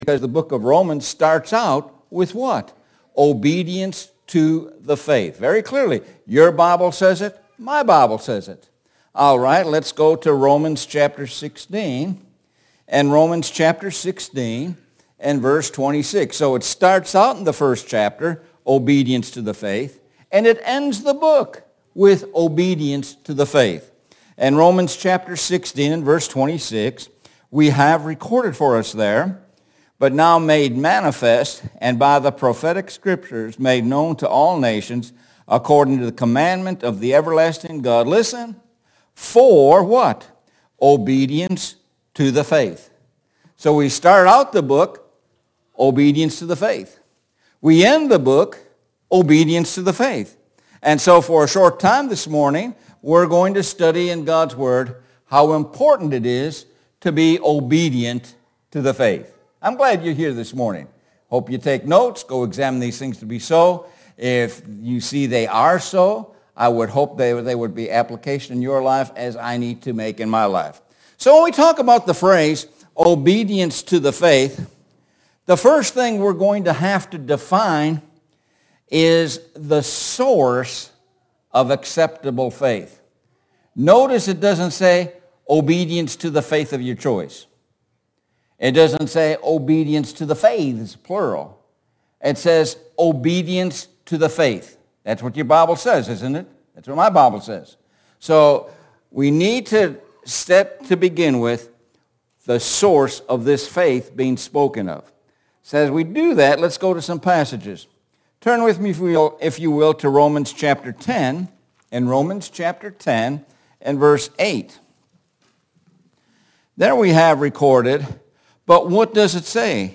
Sun AM Sermon – Obedience to the faith